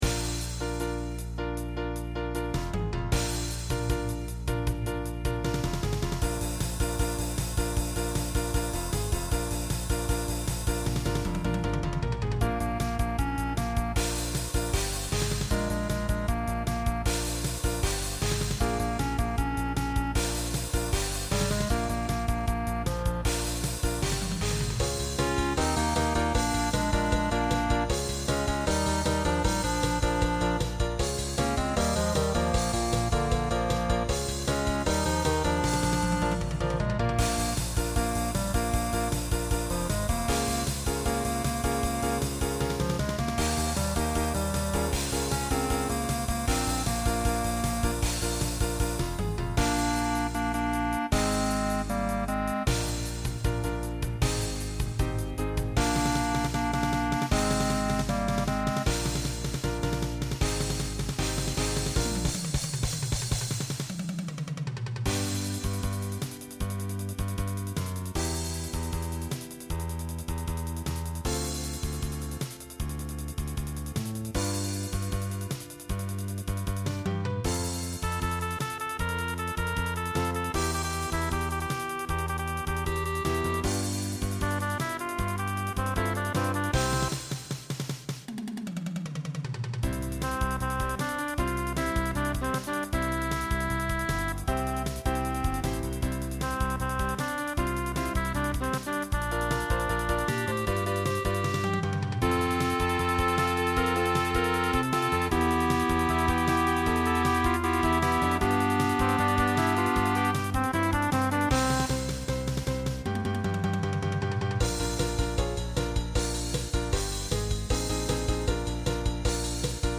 TTB/SSA
Instrumental combo Genre Rock